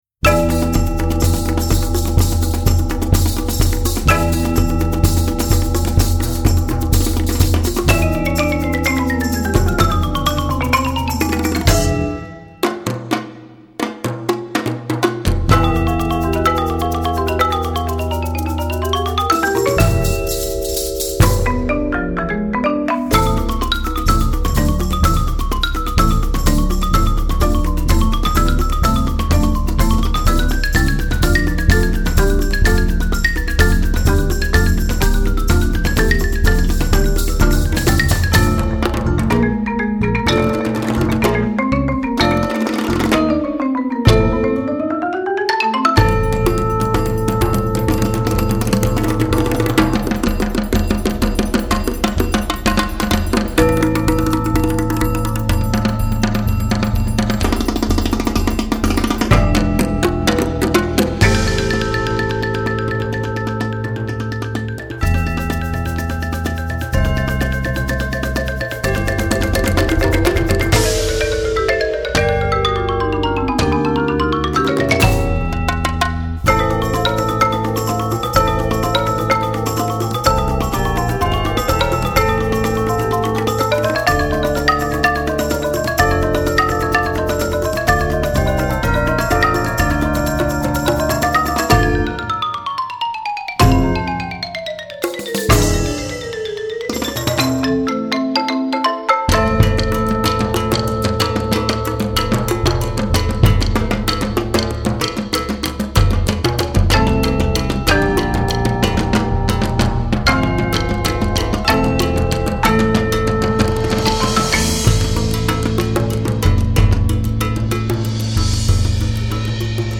Voicing: 13 Percussion